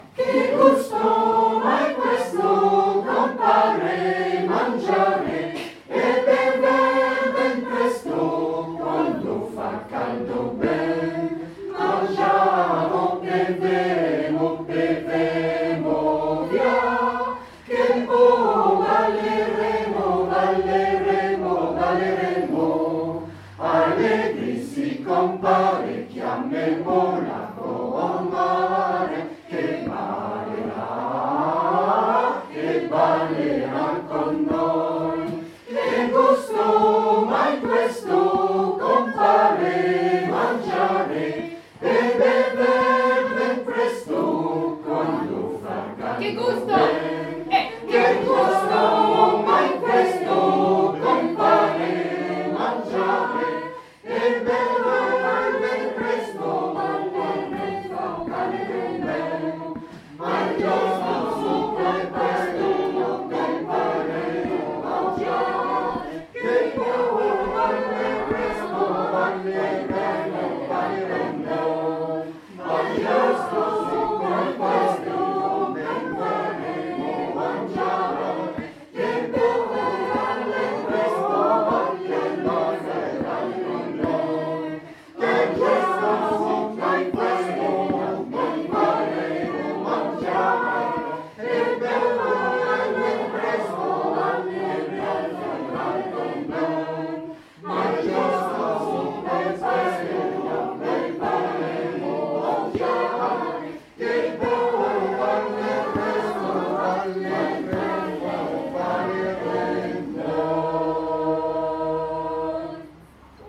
Che gusto, canon à trois voix de A. Caldara (1670-1736)